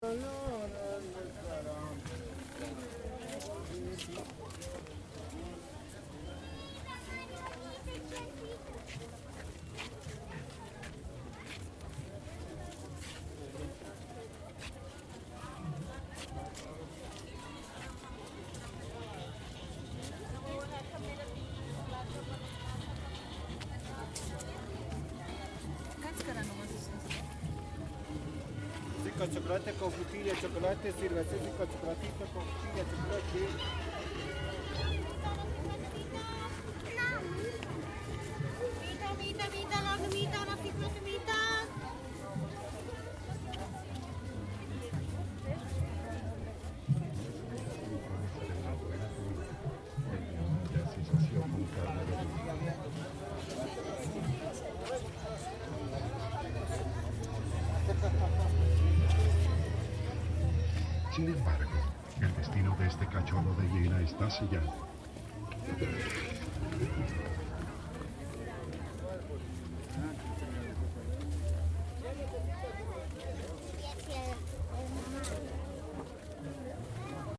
Ecoutons l’ambiance sonore de ce dimanche après-midi ensoleillé à la Feria del Avenida del 16 de Julio :